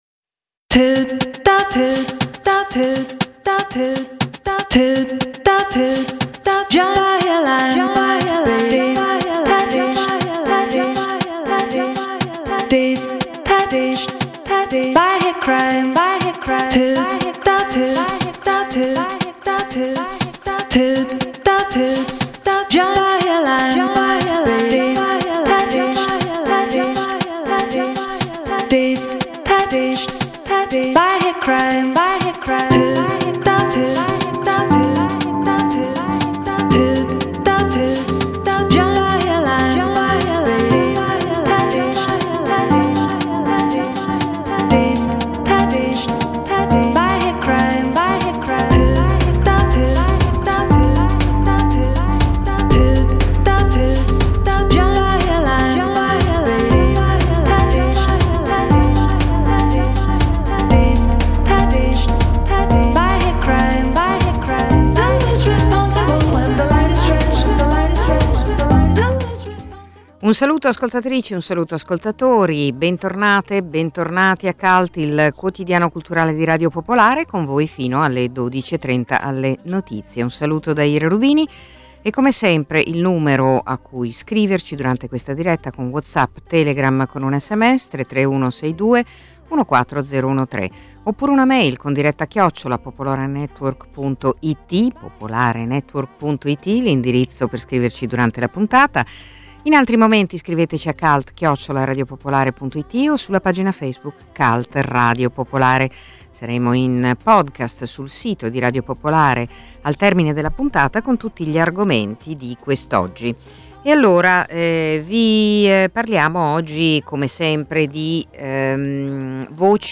Cult è il quotidiano culturale di Radio Popolare, in onda dal lunedì al venerdì dalle 11.30 alle 12.30.